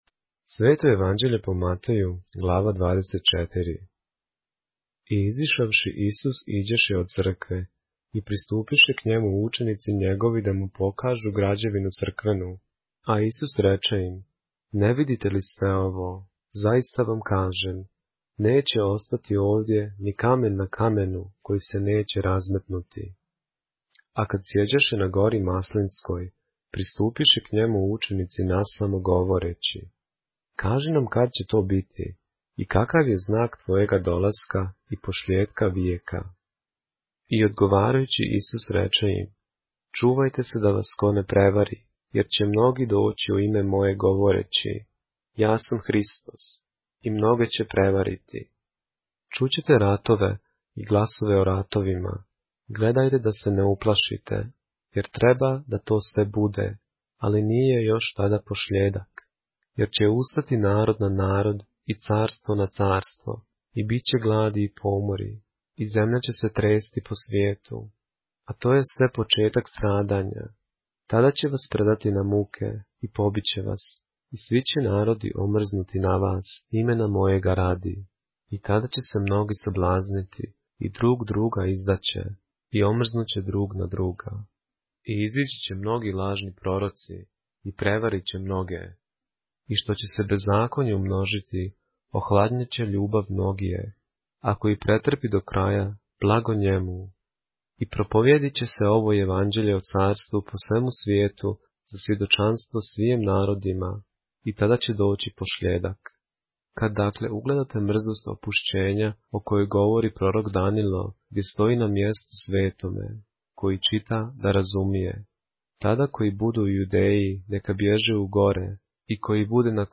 поглавље српске Библије - са аудио нарације - Matthew, chapter 24 of the Holy Bible in the Serbian language